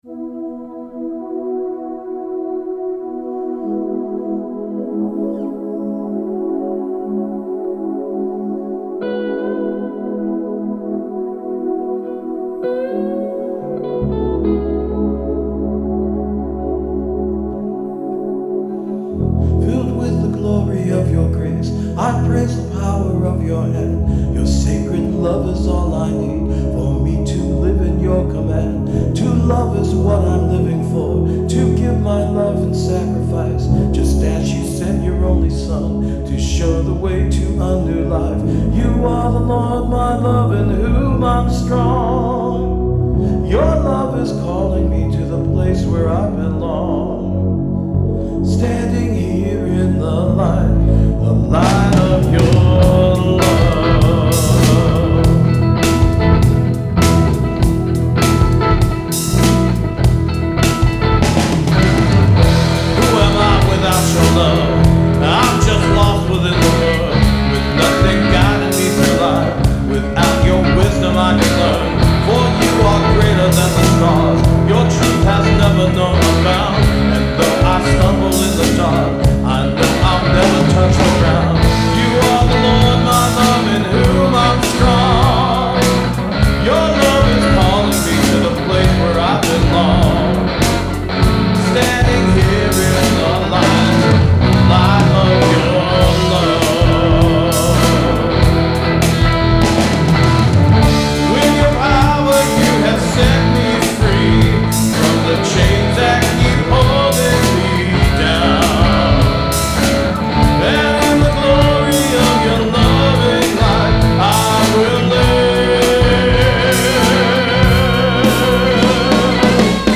New Praise Song: The Light of Your Love
As far as guitars are concerned, there’s a single one in this initial recording, and that’s my Squier CV Tele.